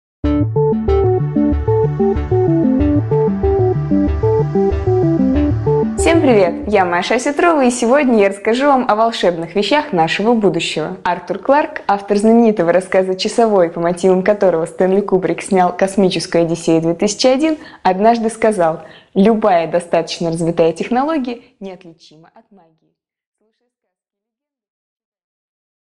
Аудиокнига 5 минут О магии и технологиях | Библиотека аудиокниг